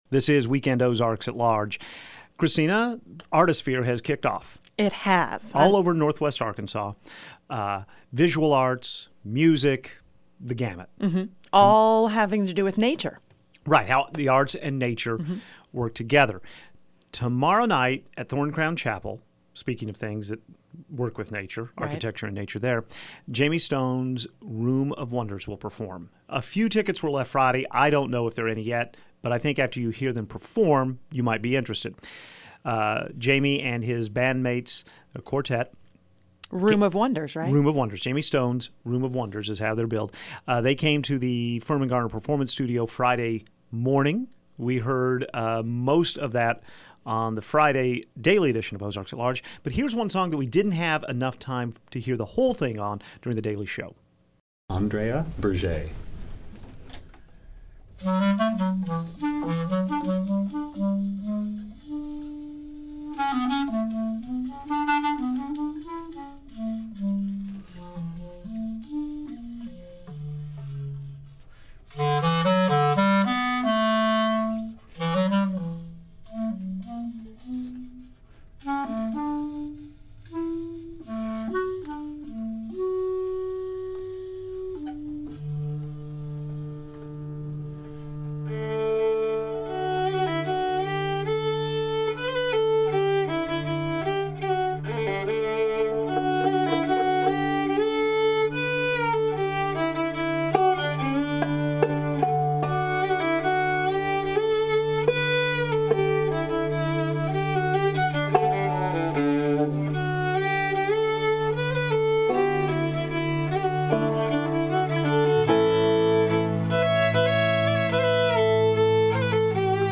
stopping by the studio